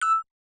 Simple Cute Alert 17.wav